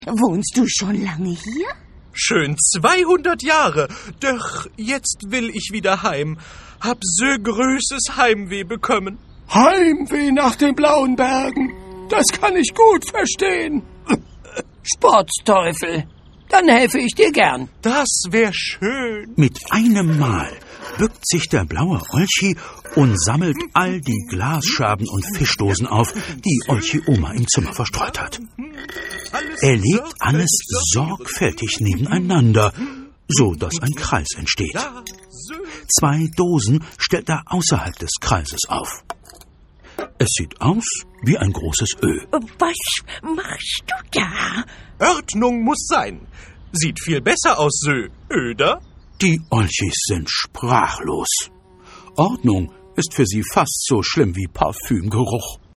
Szenische Lesung